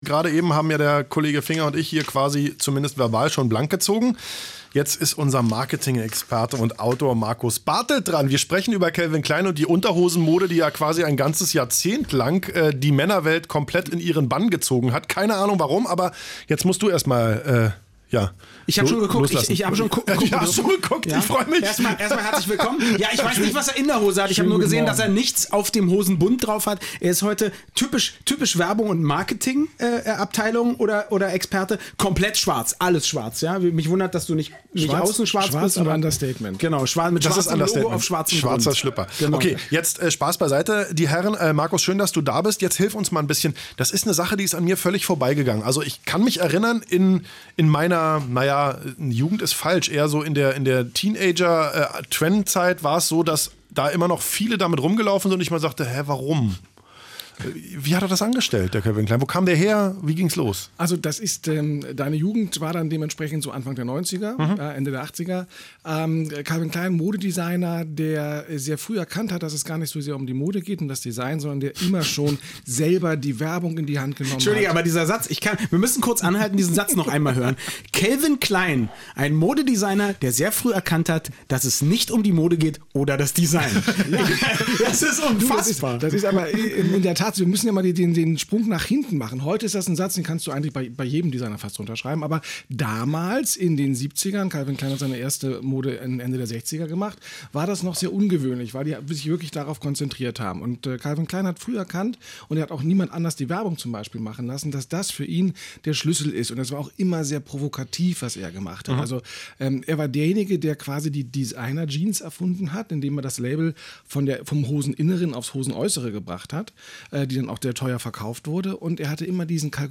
Zu Gast bei den Herren von Zweiaufeins im radioeins-Studio drehte sich diesmal alles um das Thema „klein“.